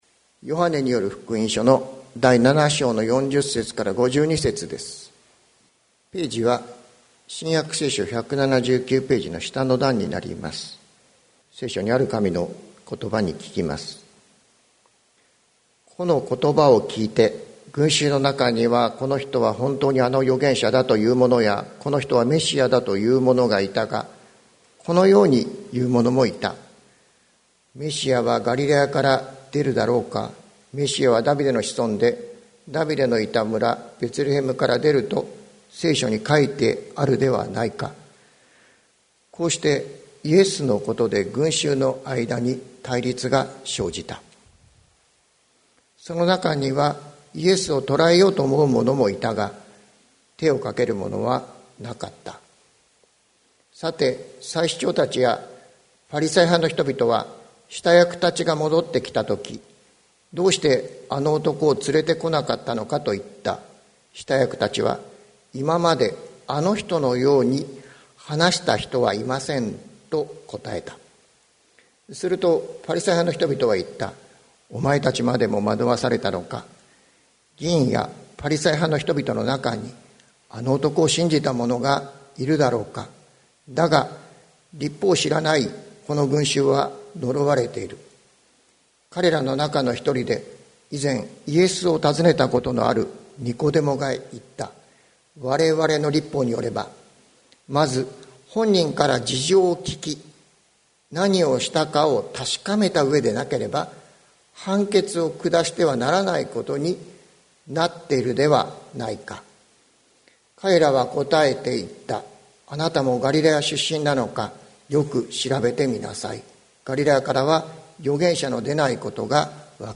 2022年07月17日朝の礼拝「こんな言葉は聞いたことがない」関キリスト教会
説教アーカイブ。